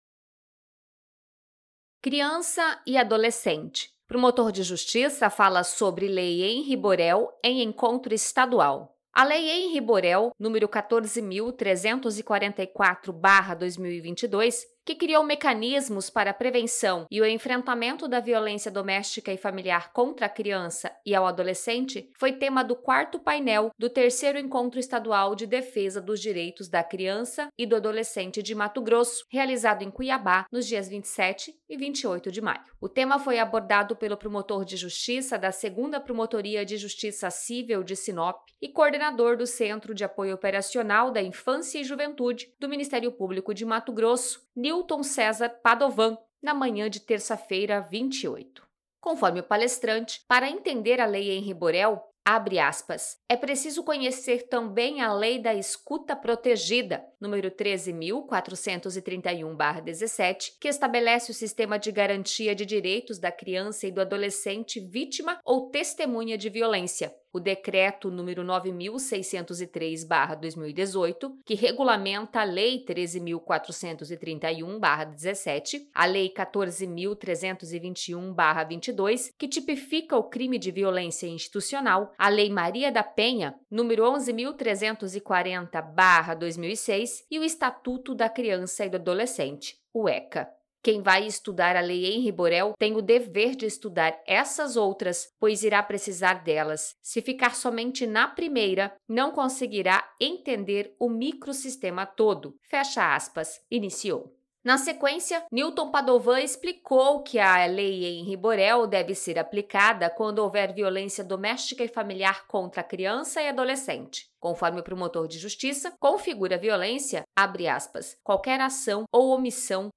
Promotor de Justiça fala sobre “Lei Henry Borel” em encontro estadual
Promotor de Justiça fala sobre “Lei Henry Borel” em encontro estadual.mp3